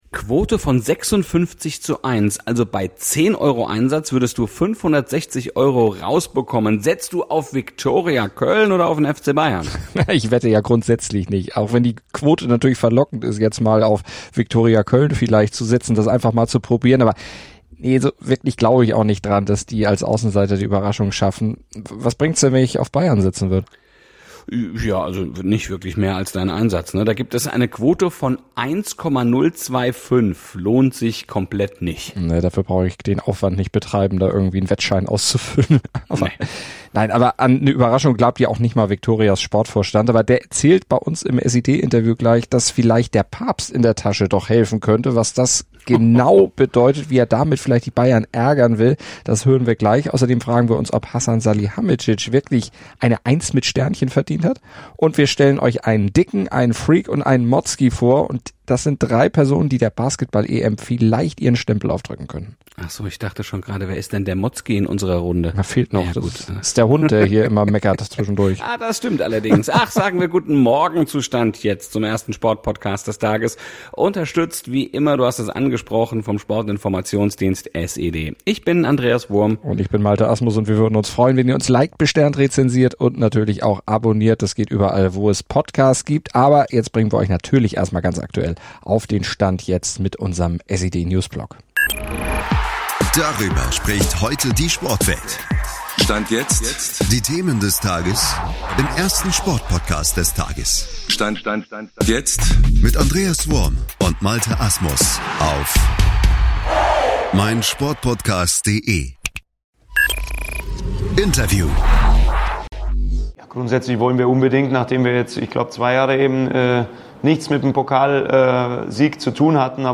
Dazu gibt es den immer aktuellen Newsblock.